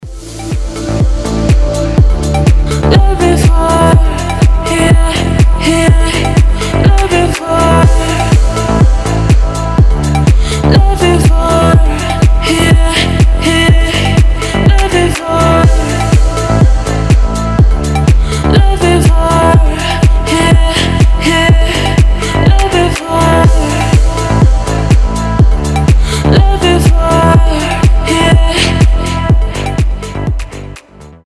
• Качество: 320, Stereo
deep house
Красивый танцевальный рингтон о любви